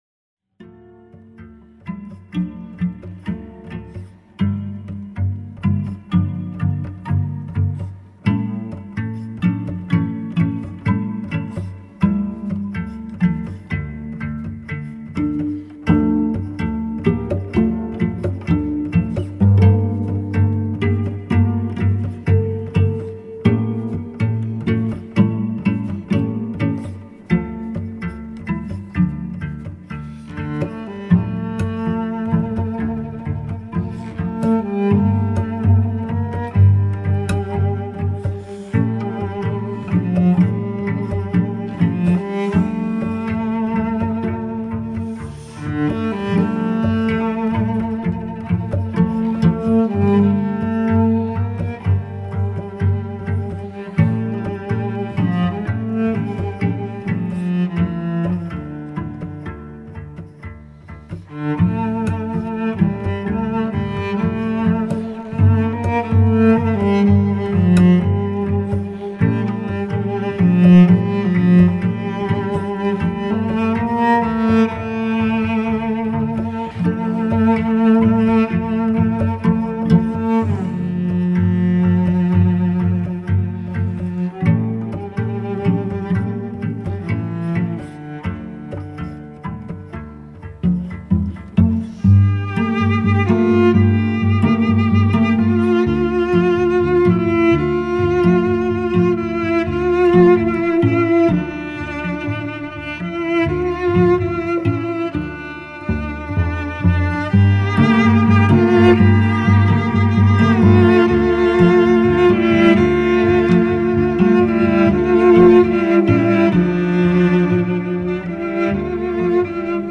epic instrumental music